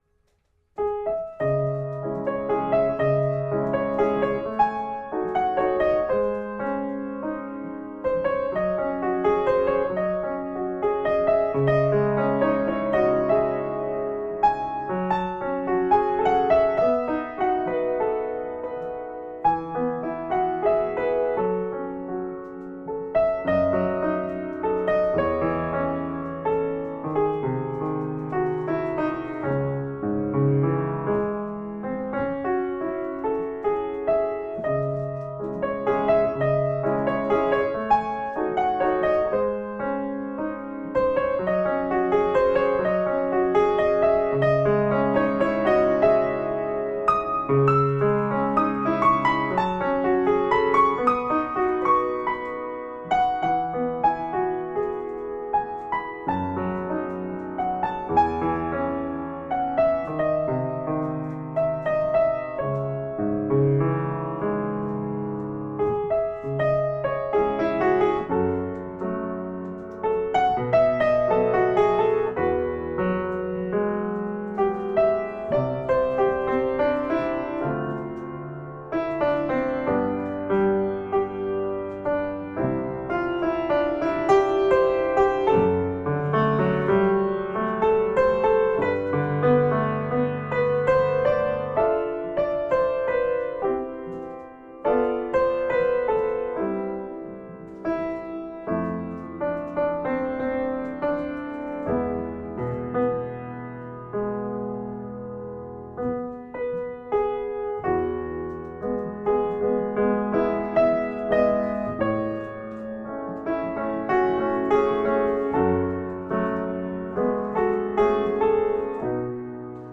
An absolutely beautiful piece of music that is so emotive and thought provoking.
A soulful sound for a deeply meanful book.